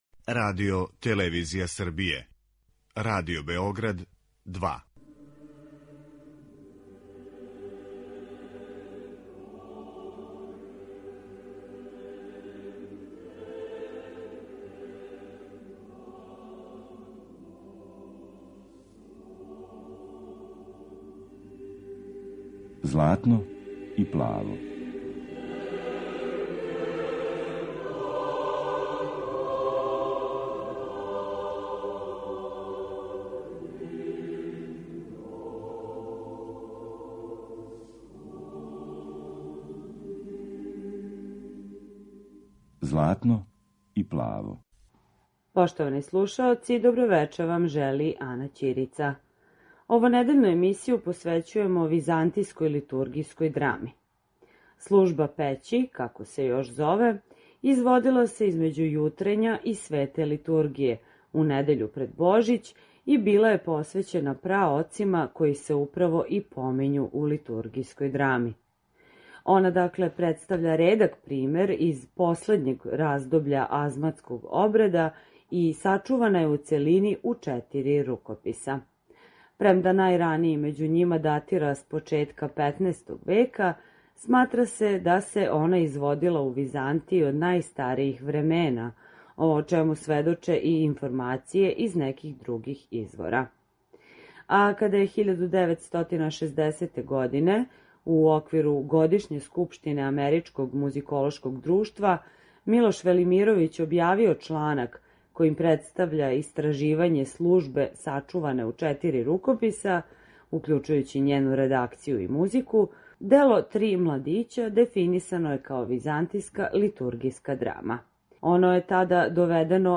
Византијска литургијска драма
Она представља редак пример из последњег раздобља азматског обреда и сачувана је у целини у четири рукописа. Емитоваћемо извођење славног Грчког византијског хора под руководством Ликургоса Ангелопулоса.